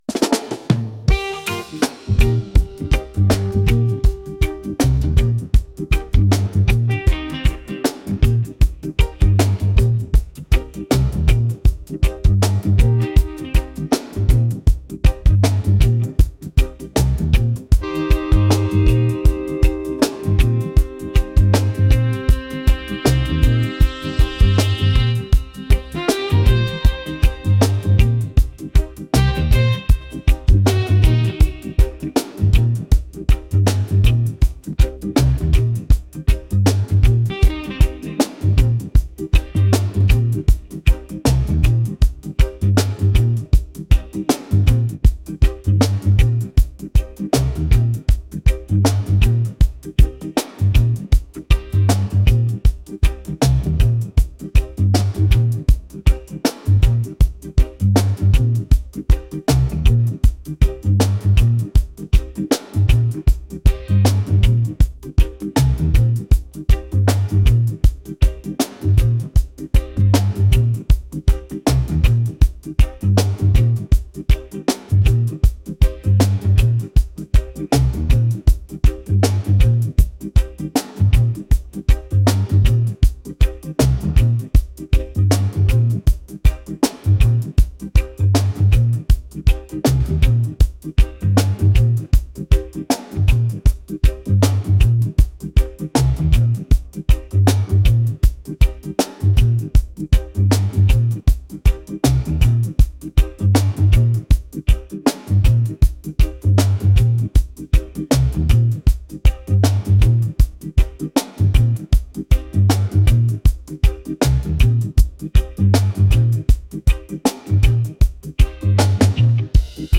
reggae | soulful | groovy